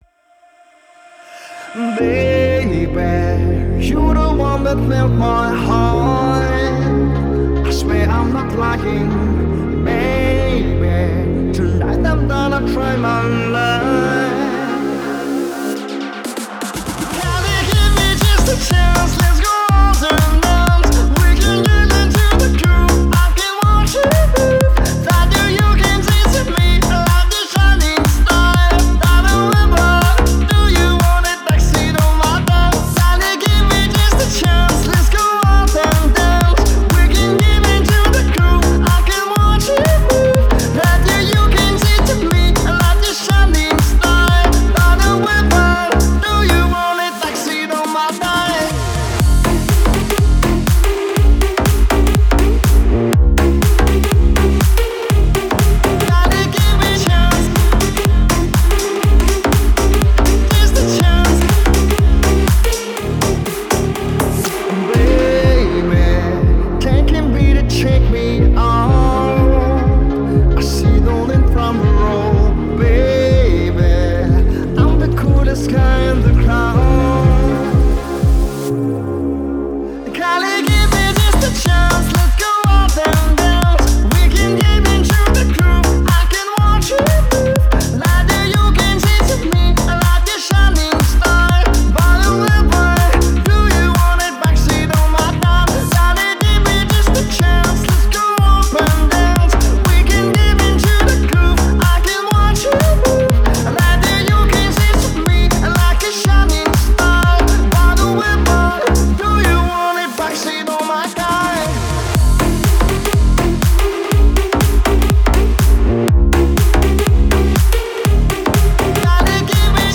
это яркий трек в жанре электронной поп-музыки